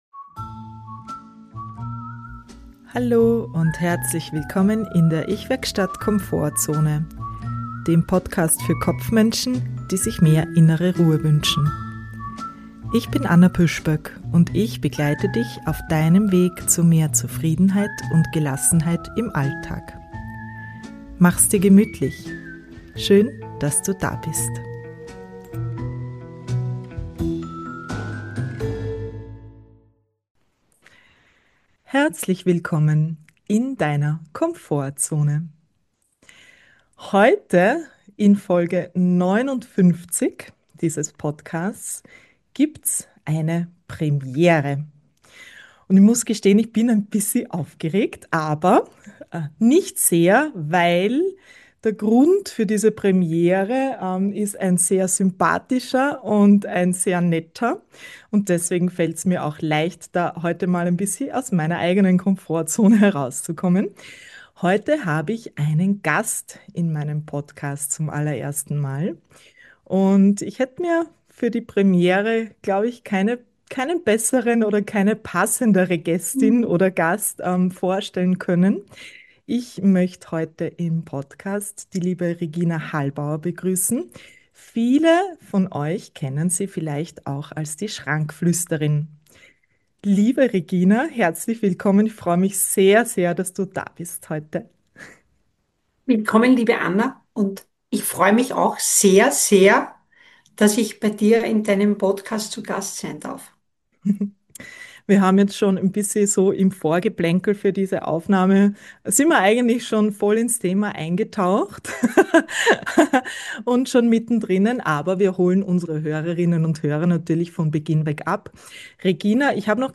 59 - Mit äußerer Ordnung zu innerer Ruhe - Ein Interview